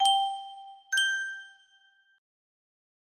note music box melody
Grand Illusions 30 (F scale)